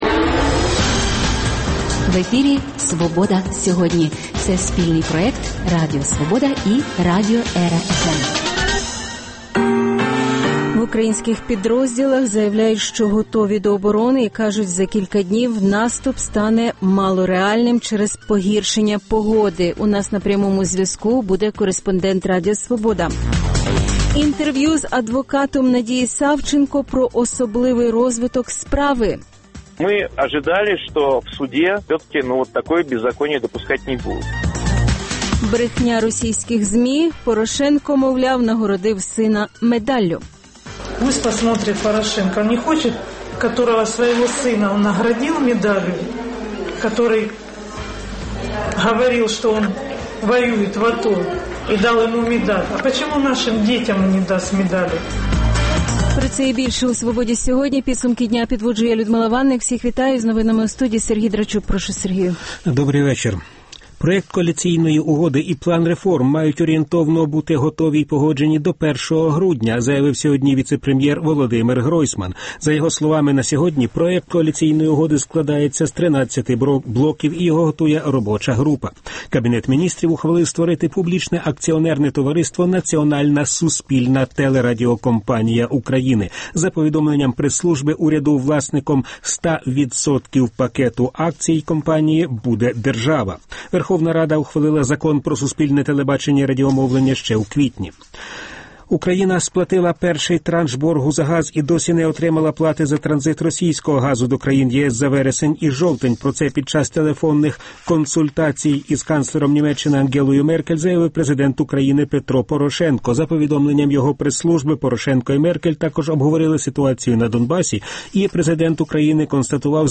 Що відбувається біля Донецького аеропорту? Почуємо з місця подій Інтерв’ю з адвокатом Надії Савченко про особливий розвиток справи